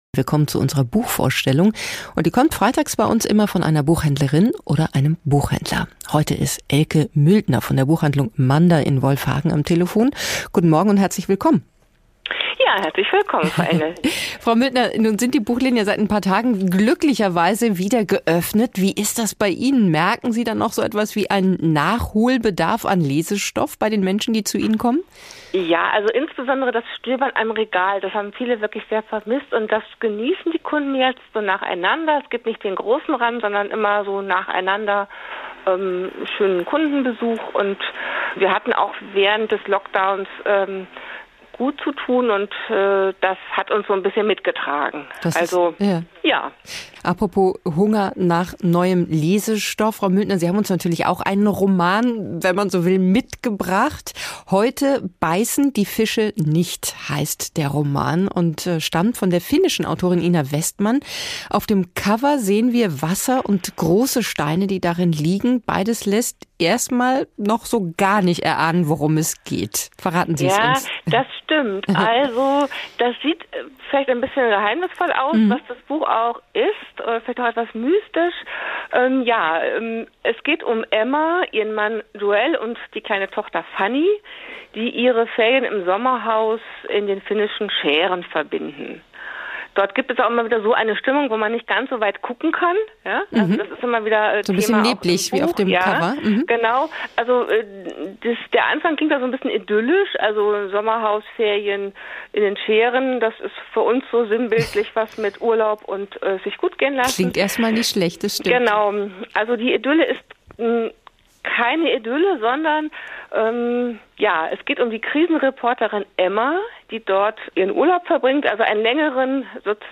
Buchvorstellung auf HR2